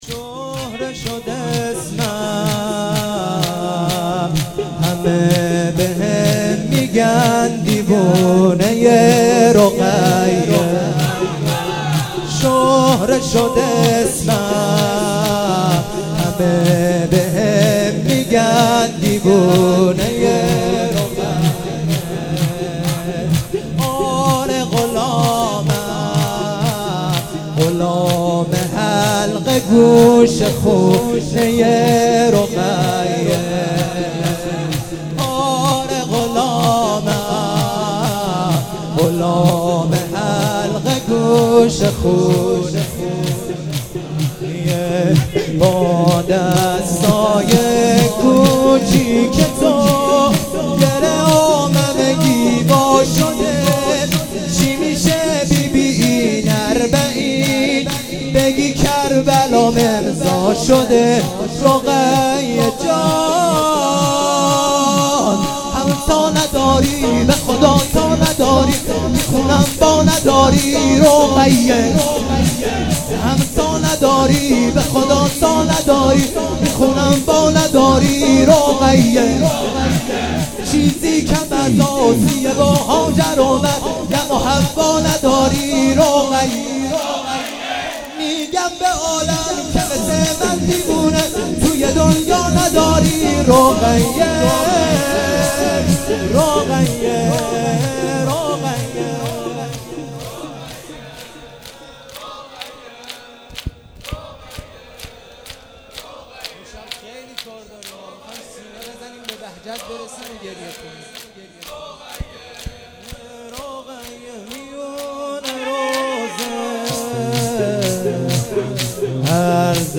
شور حضرت رقیه سلام علیها